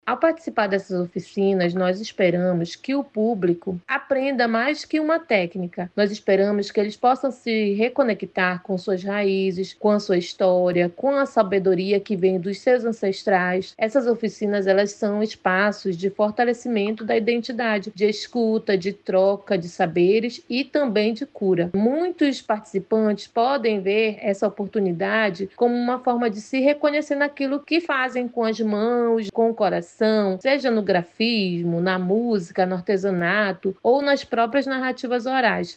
SONORA-1-OFICINAS-INDIGENAS-MAUES-.mp3